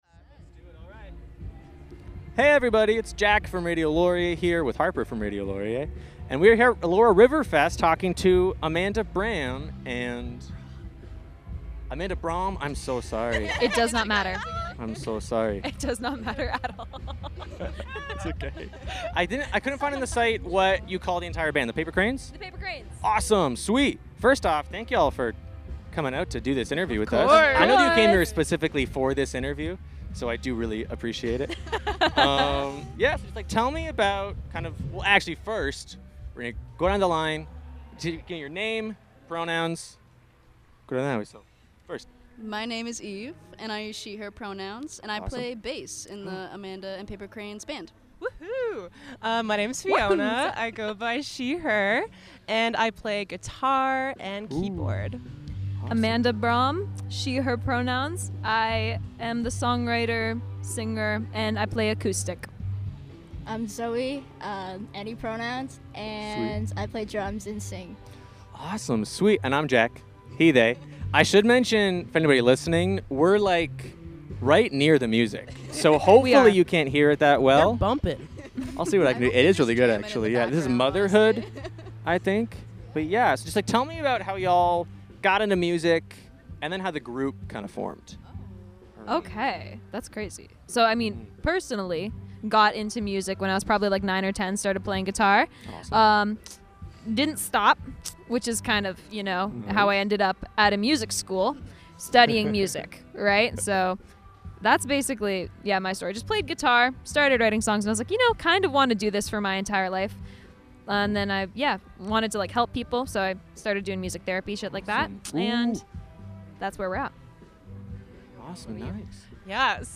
Band Interviews